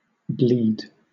wymowa:
IPA/ˈbliːd/, X-SAMPA: /"bli:d/